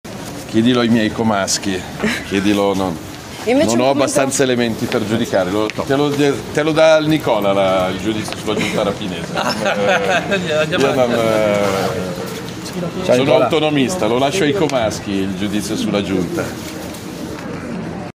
Grande partecipazione oggi al Teatro Sociale di Como per il convegno “Stato e legalità: il loro futuro è nelle nostre mani”, appuntamento clou della Settimana della Legalità organizzata dal Comune di Como e dal Sindacato Autonomo di Polizia (Sap).
Qui sotto l’audio in presa diretta: